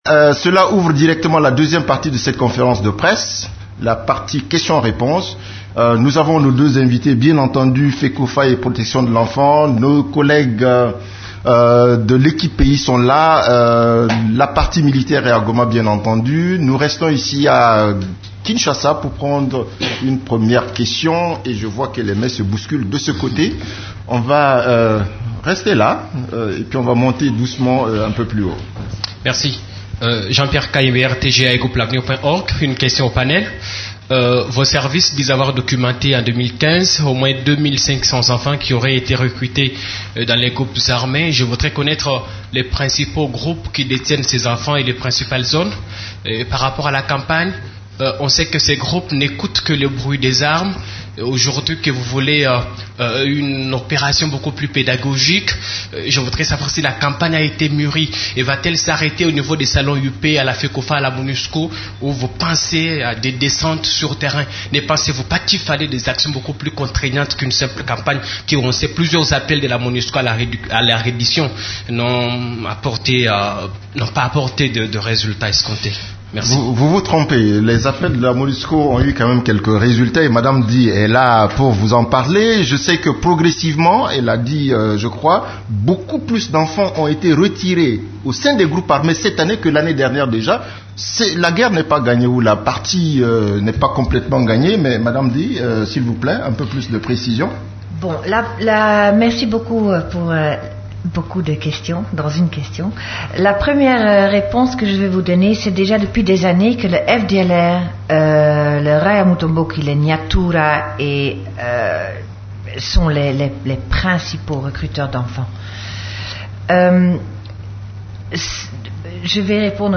Conférence de presse du 15 juin 2016
La conférence de presse hebdomadaire des Nations unies du mercredi 15 juin à Kinshasa a porté sur les activités des composantes de la MONUSCO, les activités de l’équipe-pays et la situation militaire.
Vous pouvez écouter la première partie de la conférence de presse: